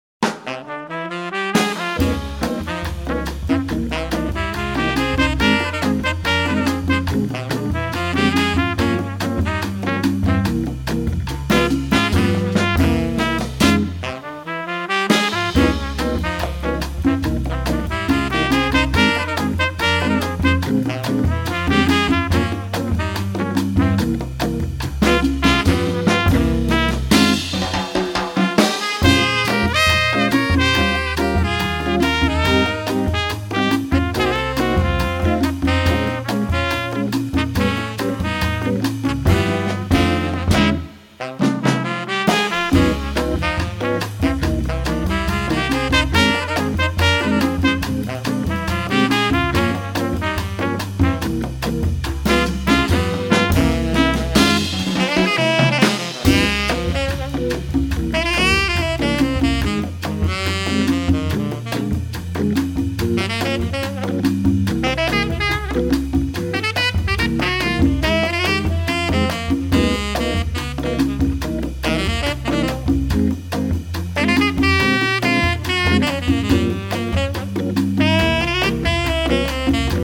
tenor and baritone saxes, vocals
trumpet
drums
percussion
Melvin Rhyne – hammond B3 organ